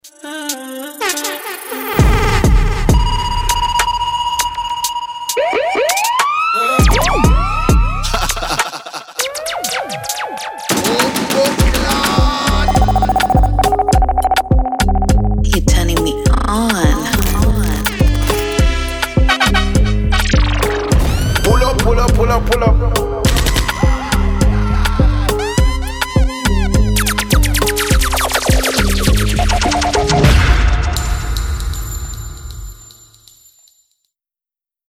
2 x Airhorn
7 x Impact
7 x Laser
6 x Siren
All samples are processed, balanced, and ready to sit perfectly in any mix.